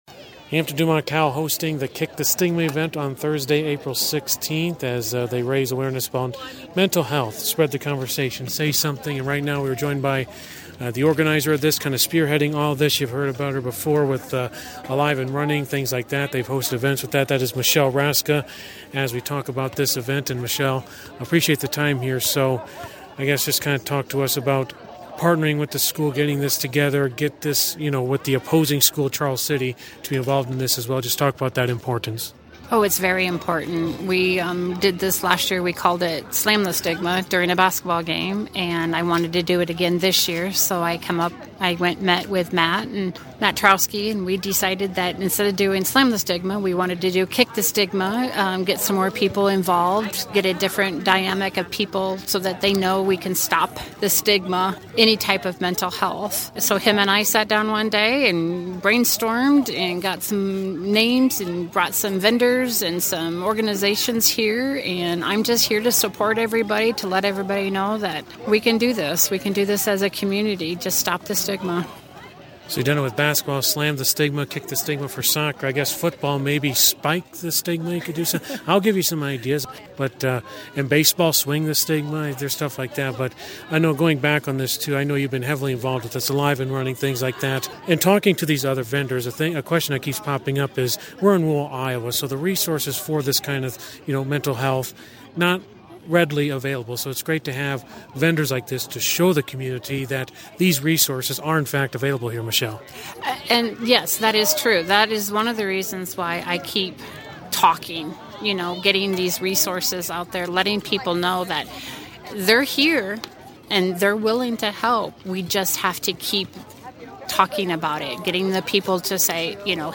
Interviews below